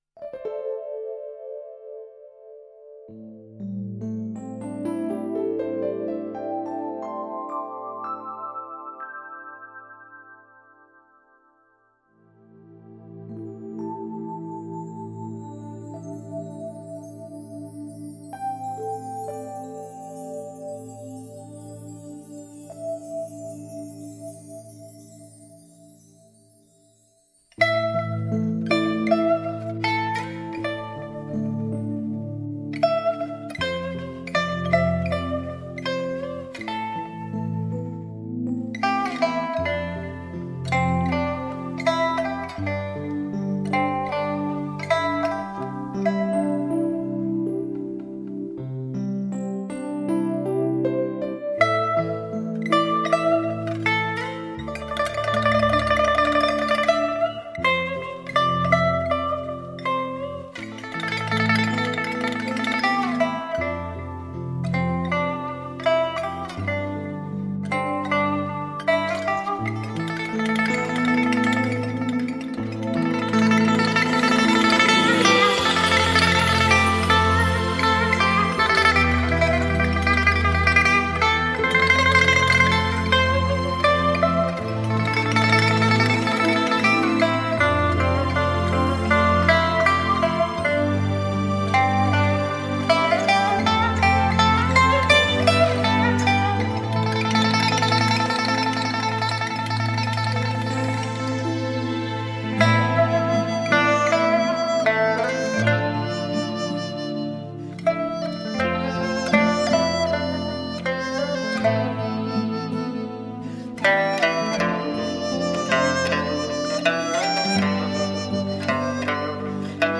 琵琶吟：此情无计可消除 才下眉头 却上心头